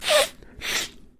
Звуки сопения